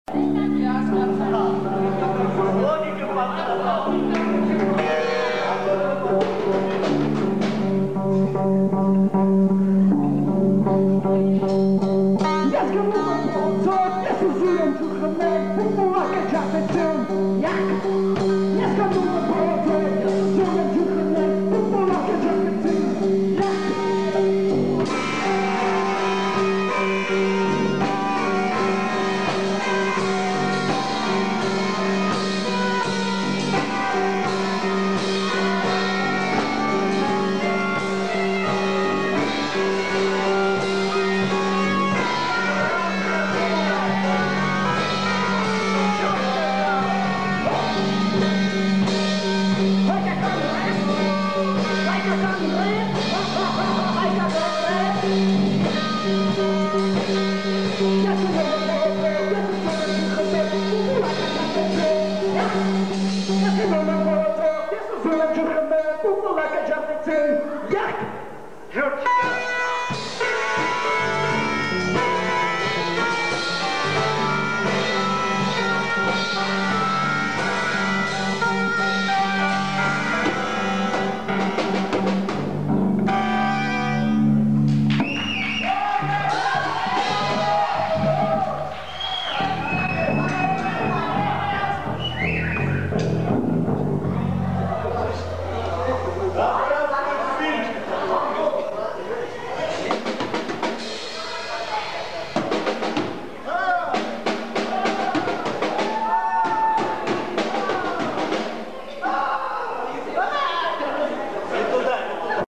КОНЦЕРТ В КИНОТЕАТРЕ "ПИОНЕР"
синтезатор
барабанная установка